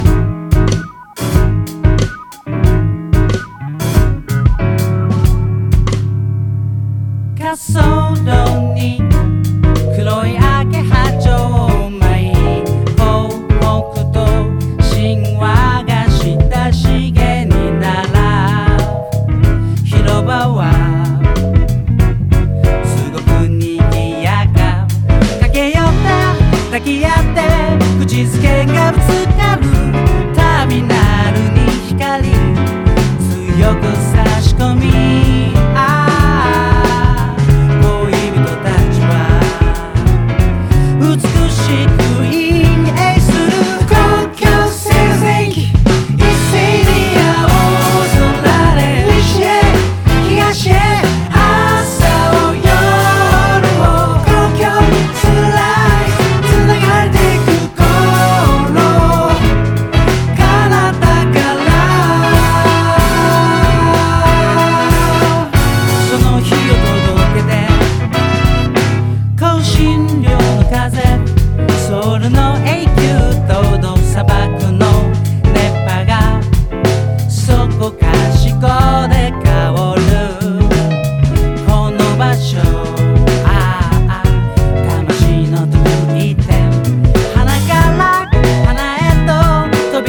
JAPANESE REGGAE / CITY POP (JPN)